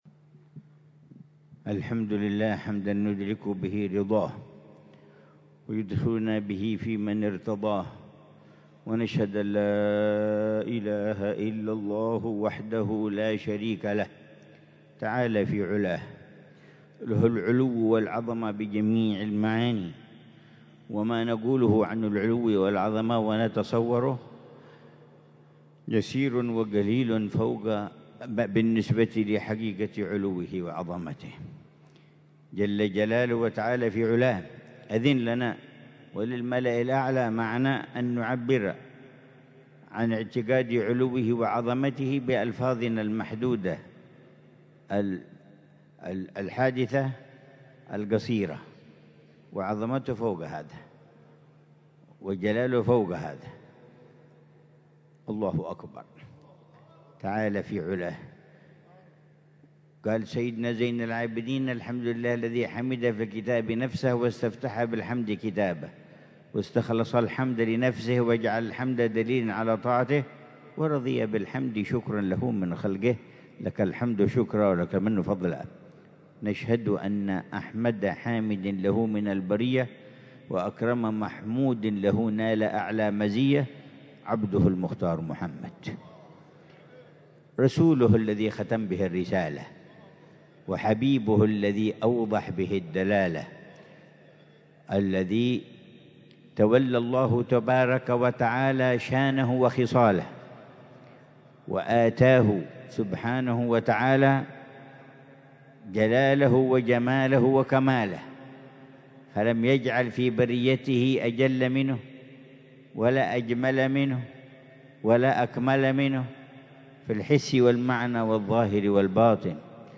محاضرة العلامة الحبيب عمر بن محمد بن حفيظ ضمن سلسلة إرشادات السلوك ليلة الجمعة 18 ربيع الثاني 1447هـ في دار المصطفى، بعنوان: